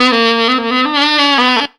TRILLIN 1.wav